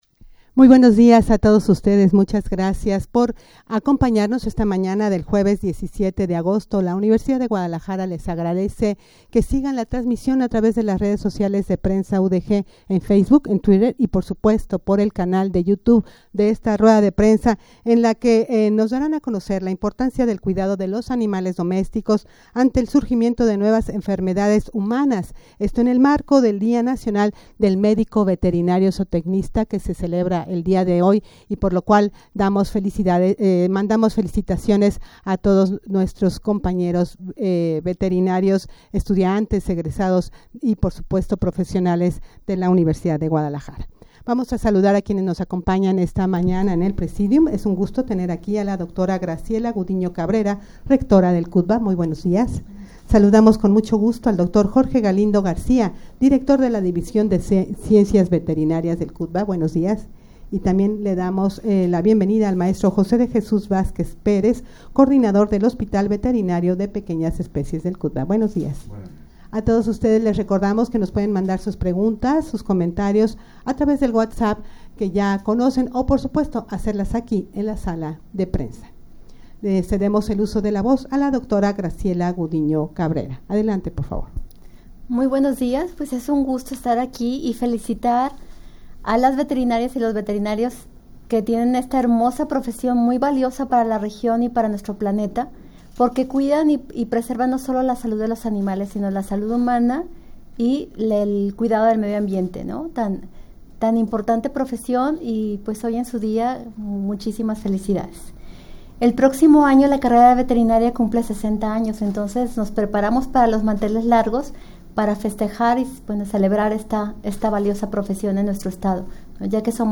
Audio de la Rueda de Prensa
rueda-de-prensa-para-dar-a-conocer-la-importancia-del-cuidado-de-los-animales-domesticos.mp3